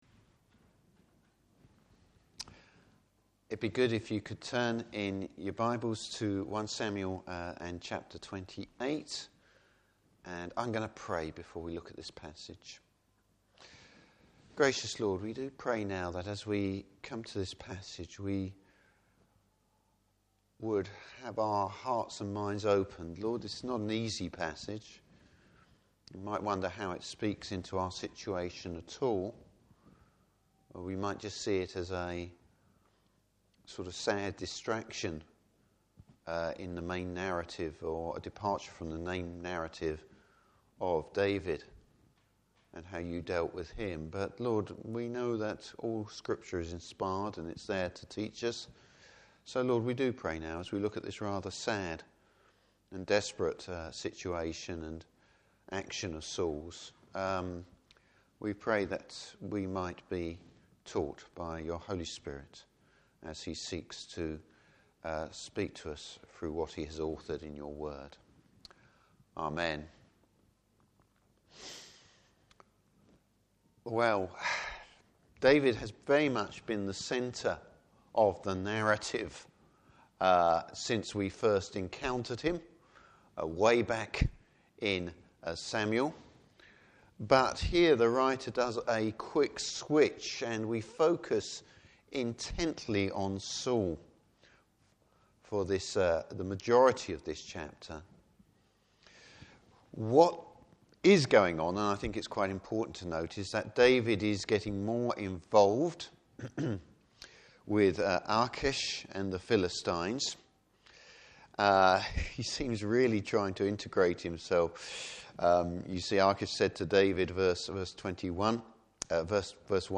Passage: 1 Samuel 28. Service Type: Evening Service Saul’s sad decline!